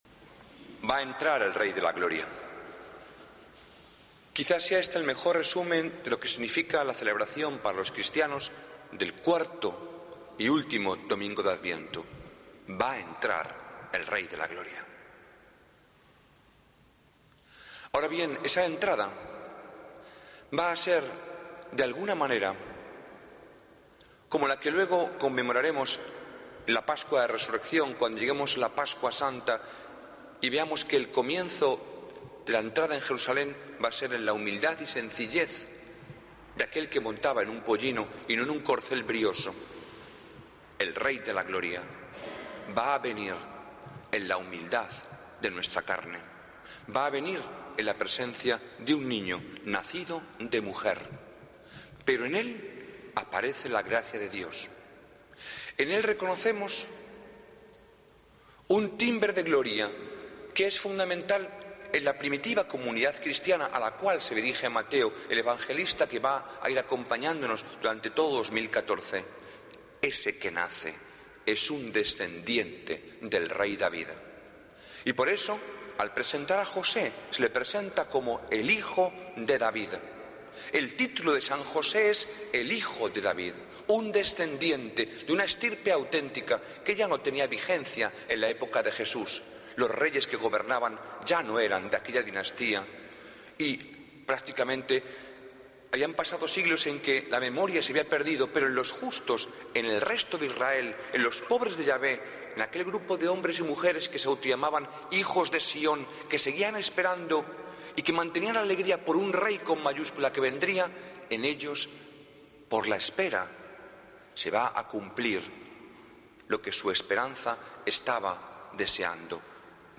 Homilía del 22 de Diciembre de 2013